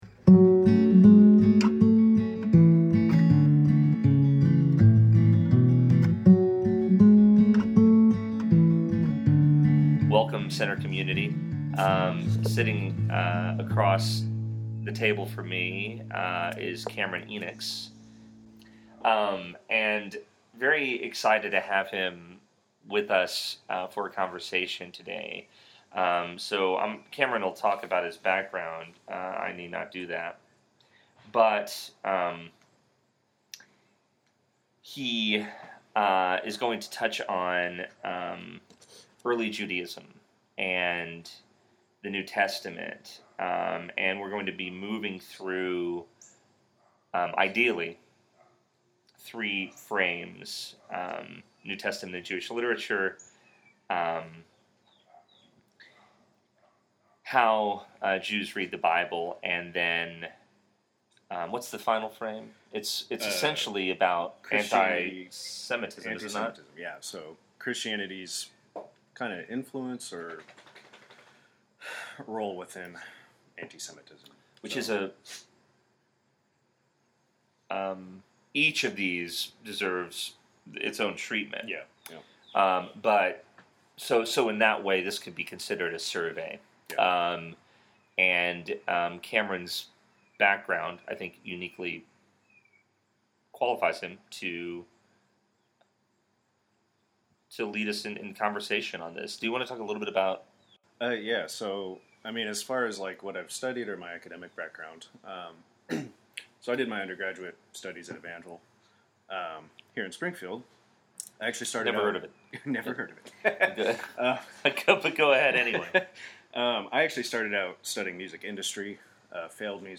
Ancient Judaism | A Conversation